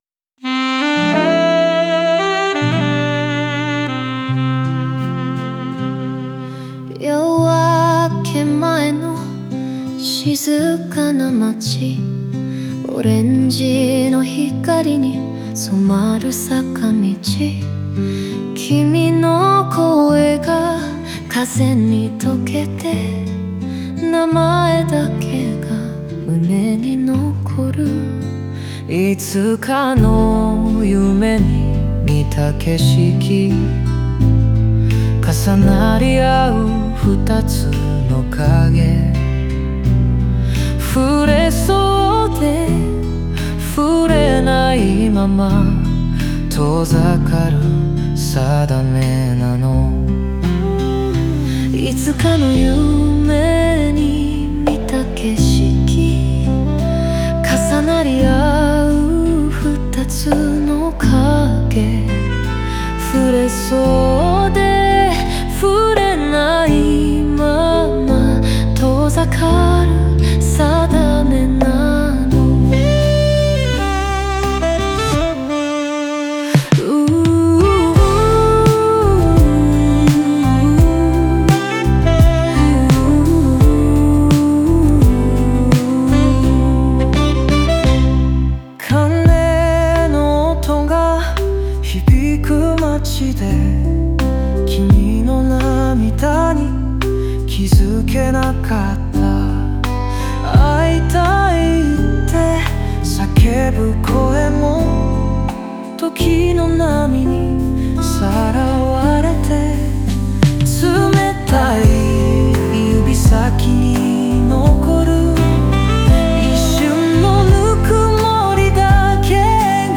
オリジナル曲♪
二人の歌声が交差することで、現在・過去・未来がつながり、ラストでは希望の余韻を残します。